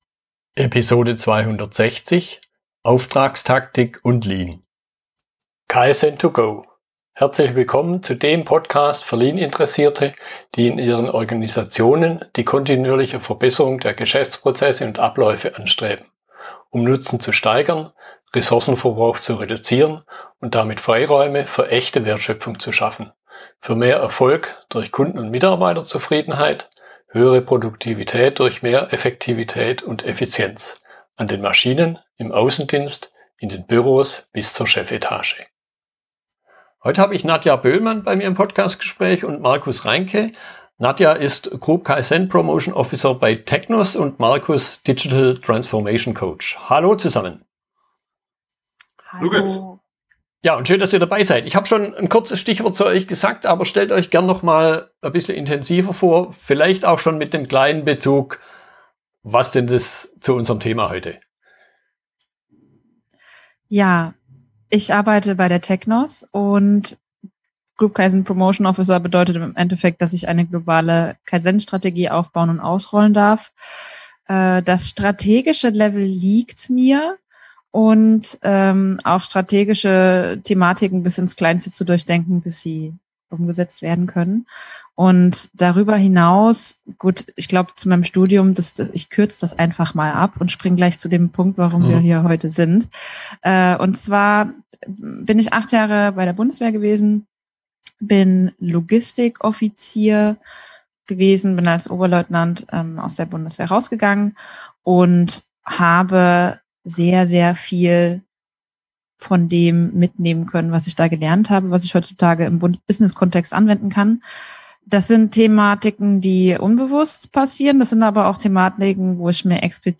Fragestellungen aus dem Gespräch